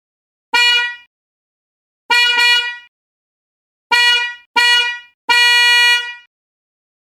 ※音は試聴用に録音したもので実際の吹鳴音と異なる場合があります。
渦巻ホーンの特長である柔らかなサウンドを奏でます。
480Hz